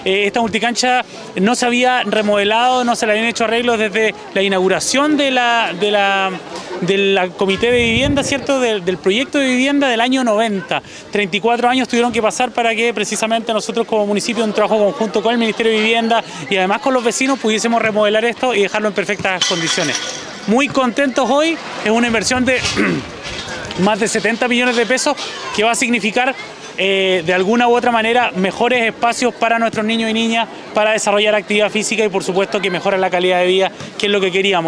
Alcalde de Coyhaique